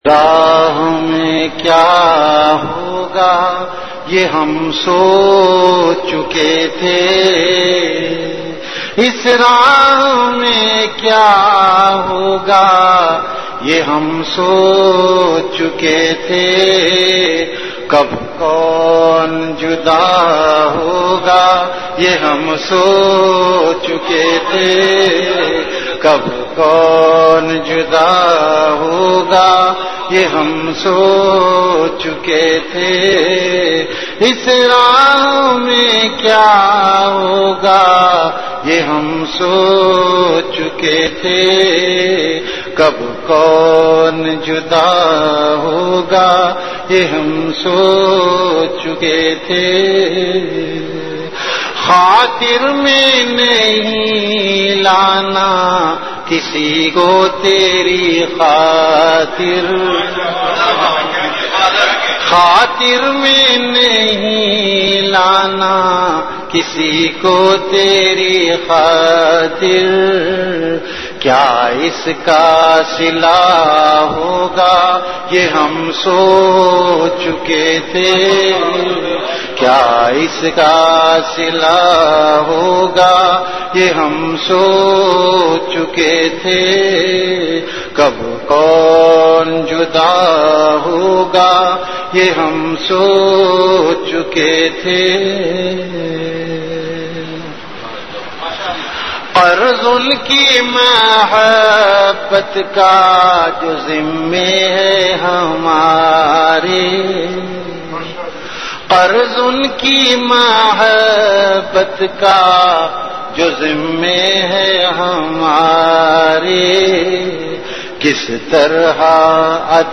CategoryAshaar
VenueKhanqah Imdadia Ashrafia
Event / TimeAfter Isha Prayer